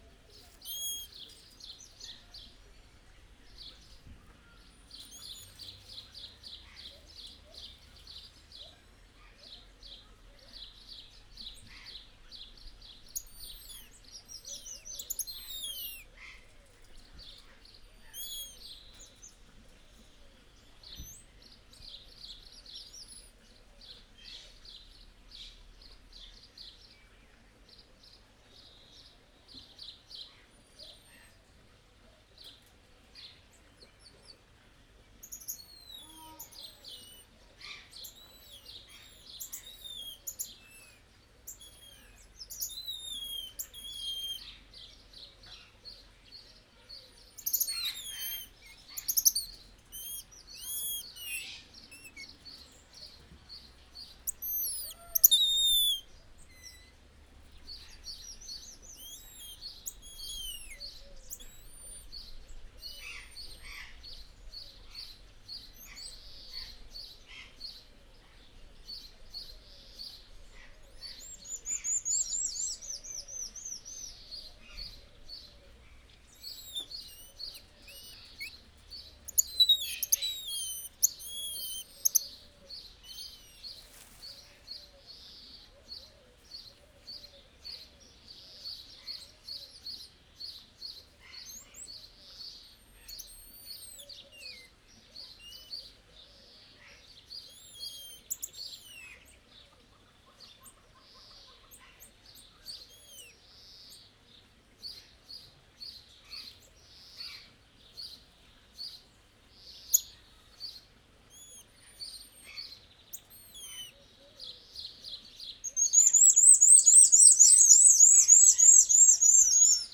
Directory Listing of /_MP3/allathangok/jaszberenyizoo2016_professzionalis/csaszarbajszutamarin/
idokozonkent_erdekeshangzasuavege02.02.WAV